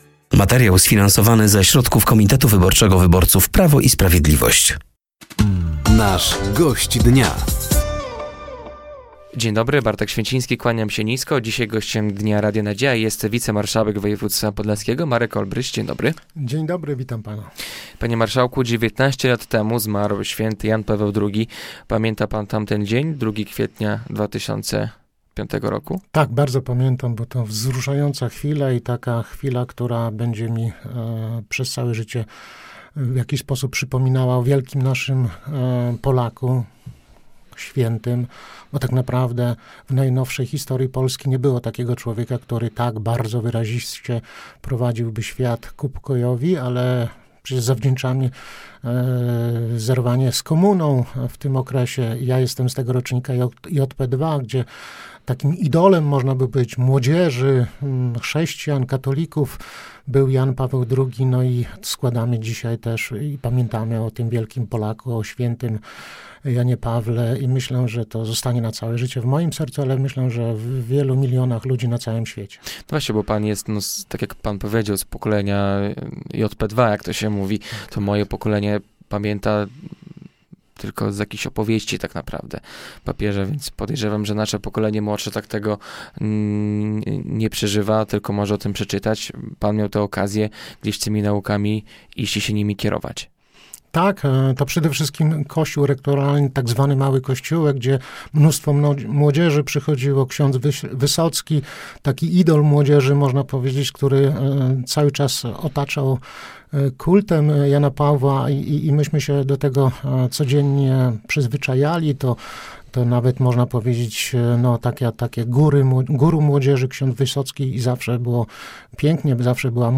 Gościem Dnia Radia Nadzieja był wicemarszałek województwa podlaskiego Marek Olbryś. Tematem rozmowy były nadchodzące wybory samorządowe, sondaże i plany na rozwój województwa.